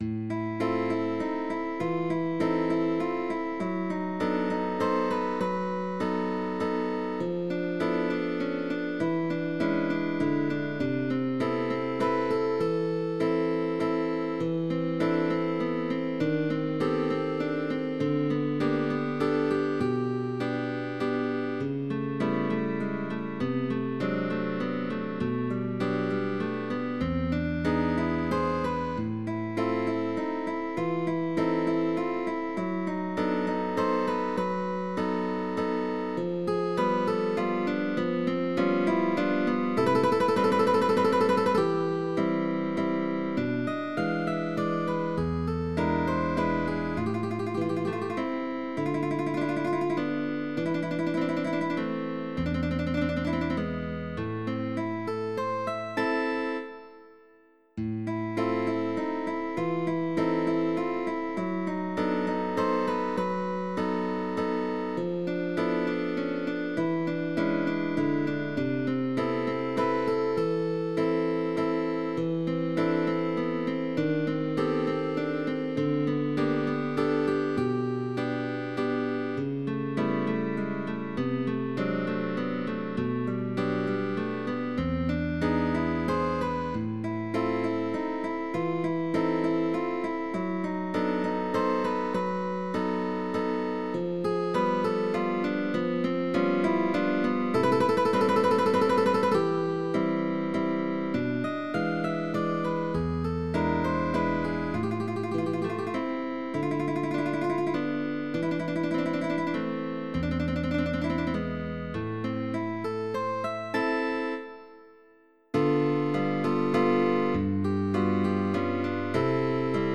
waltz